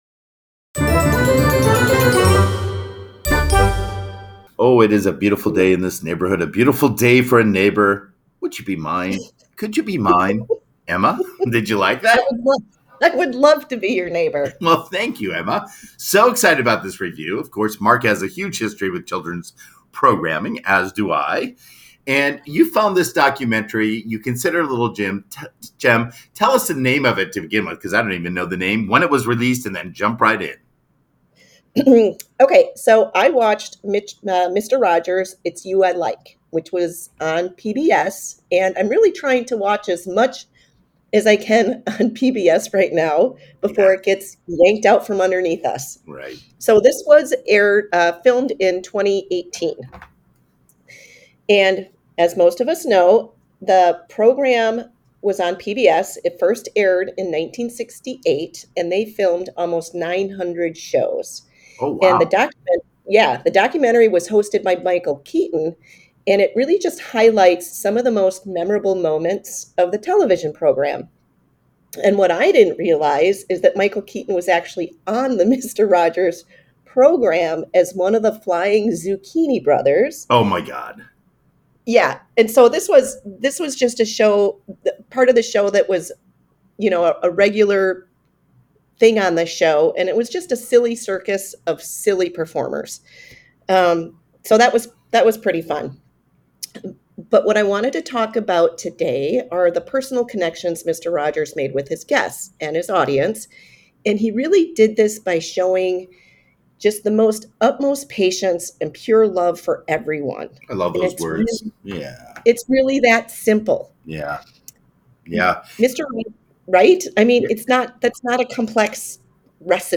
reviews and analysis of movies, TV, documentaries and more